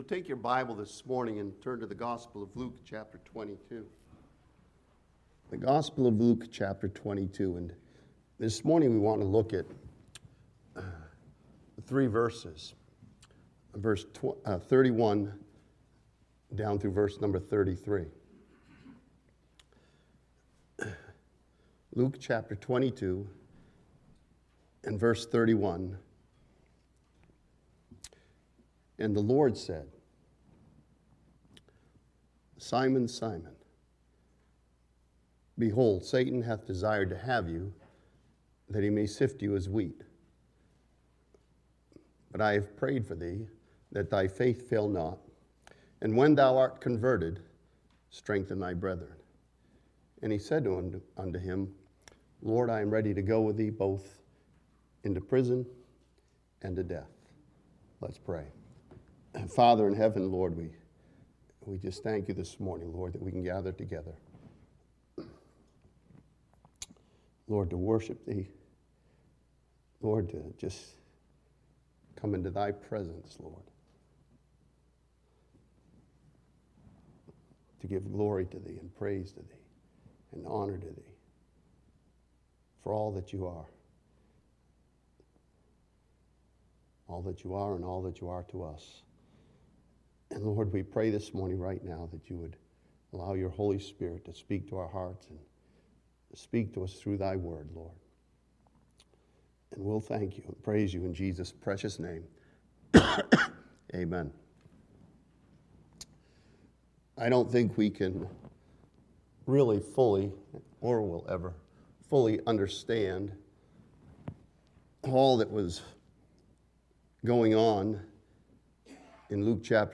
This sermon from Luke chapter 22 studies the Lord's words to Peter and sees the truth that the Lord upholds His children.